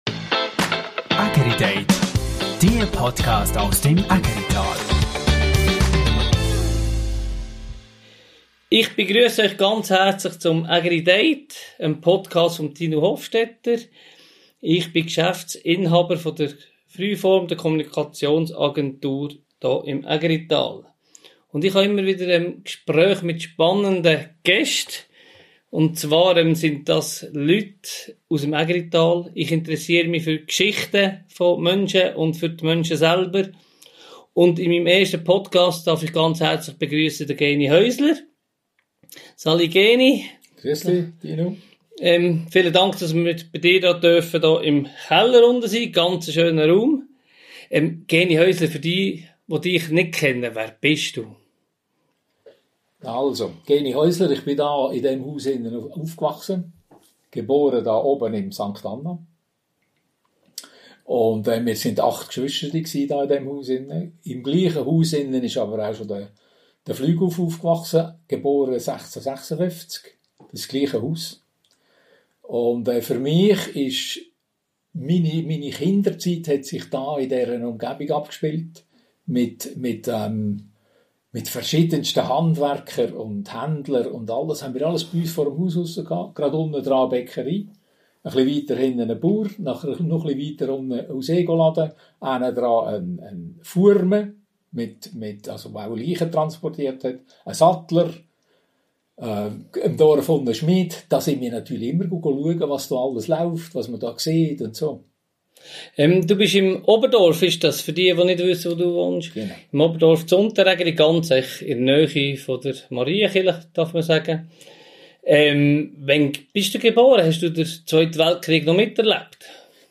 Ein Gespräch voller Geschichten, Anekdoten und Ägerer Charme.